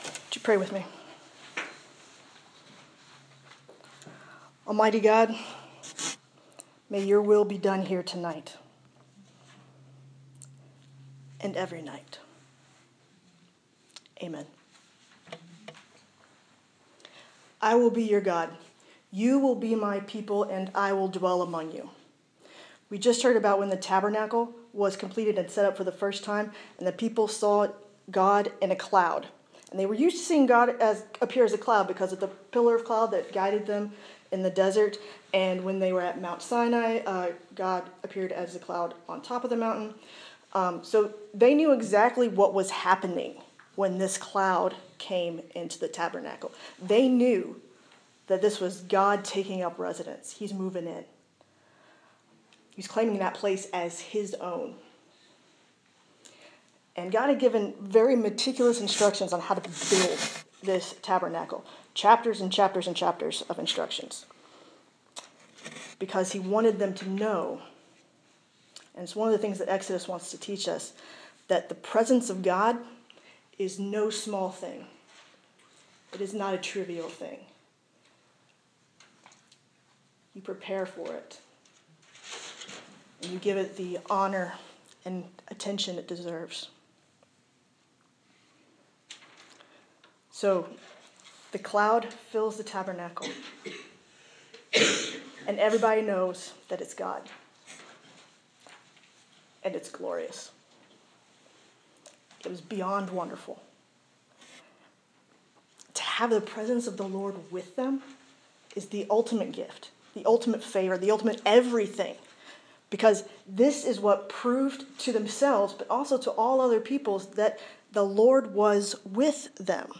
This sermon was given on the first night of a special 4 week service offering on Wednesday nights. There is a chunk of the recording in which you can hear the very loud heater running.